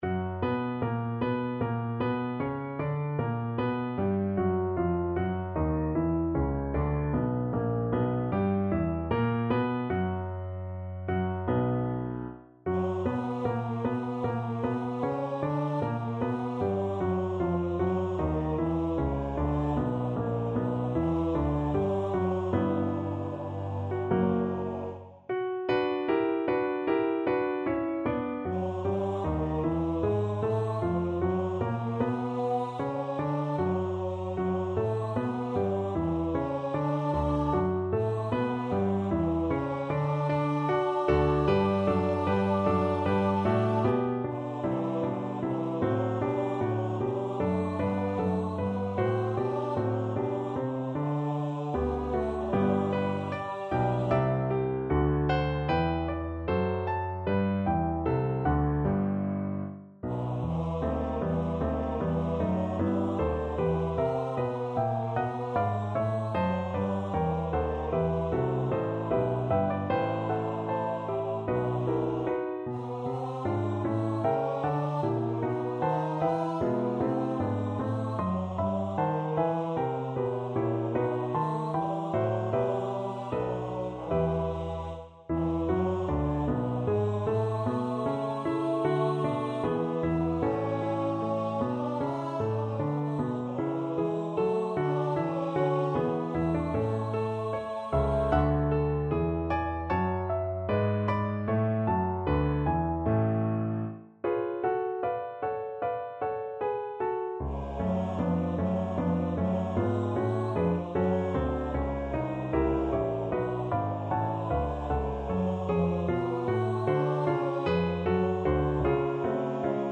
Bass Voice
B minor (Sounding Pitch) (View more B minor Music for Bass Voice )
Larghetto (=76)
4/4 (View more 4/4 Music)
Classical (View more Classical Bass Voice Music)